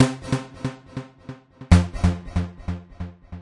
描述：用reFX Vanguard制作的旋律。声音二。
Tag: 标题 舞蹈 DJ melodics 捻线机 高潮 轨道 恍惚 恍惚门 旋律